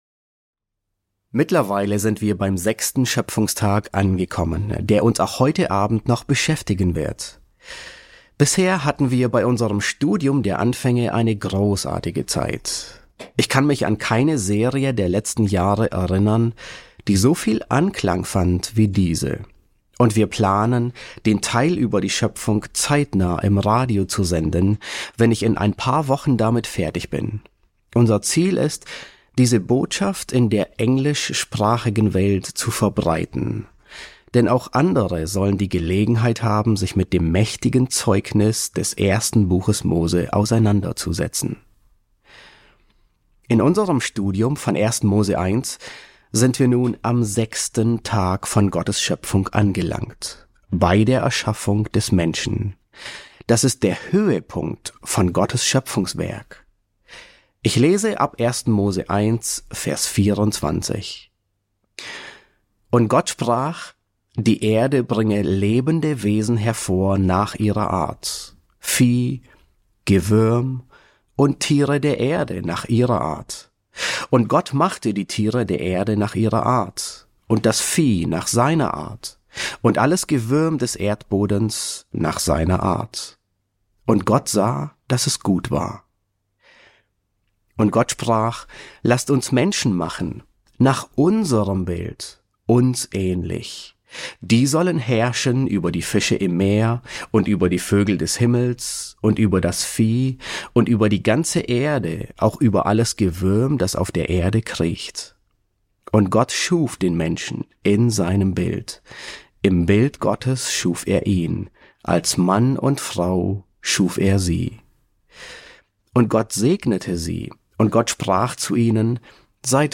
E11 S6 | Der 6. Schöpfungstag, Teil 2 ~ John MacArthur Predigten auf Deutsch Podcast